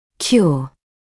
[kjuə][кйуэ]лекарство, средство исцеления; излечивать, исцелять